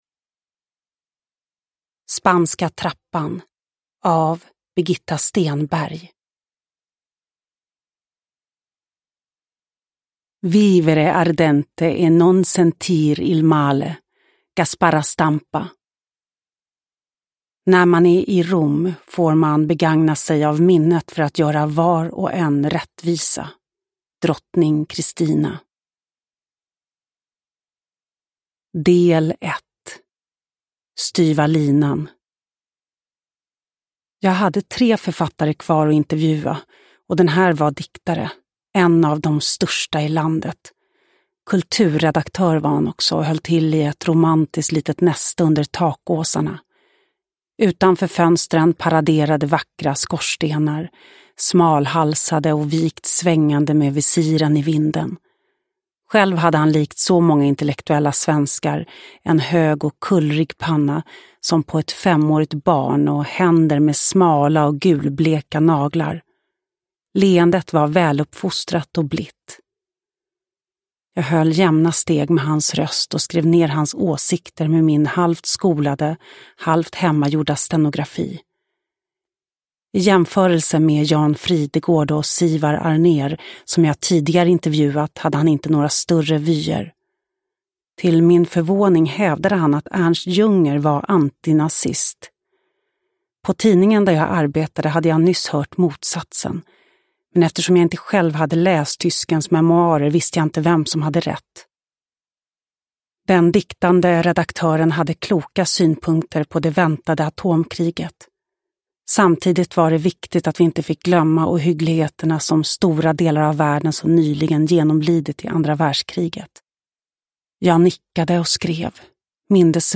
Uppläsare: Lo Kauppi